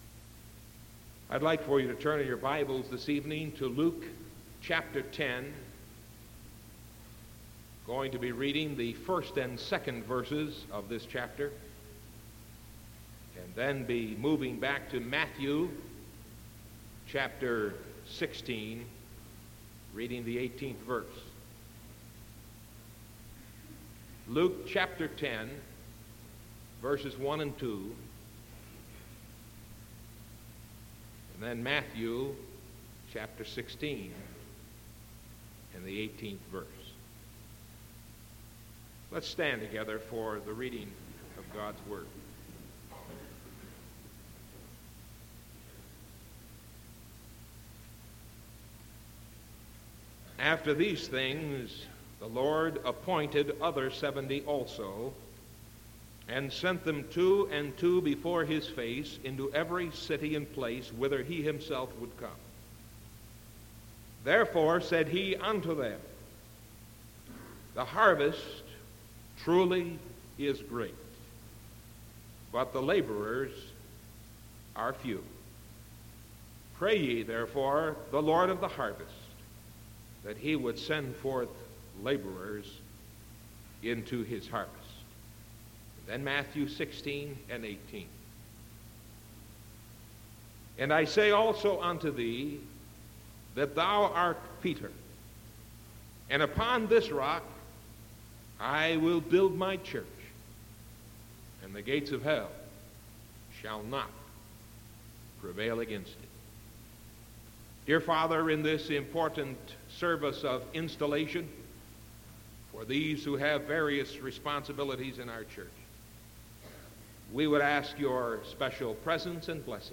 Sermon September 29th 1974 PM